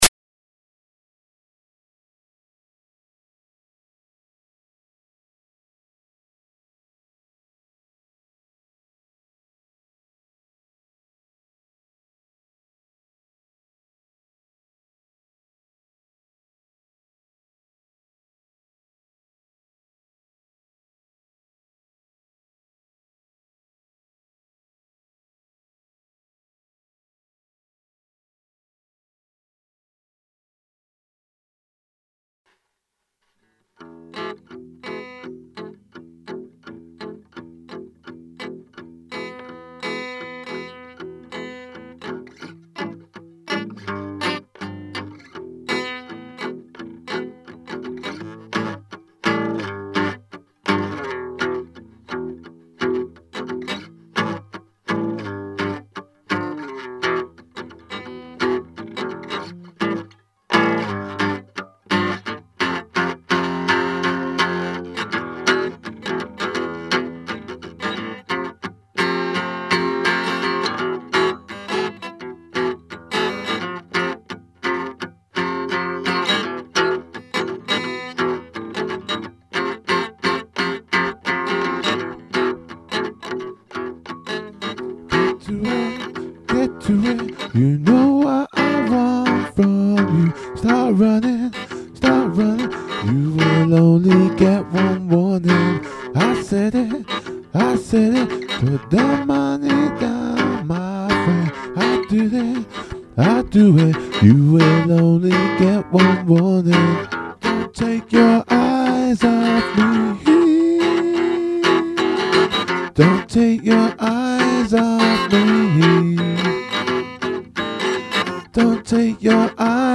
Rock & Roll
Indy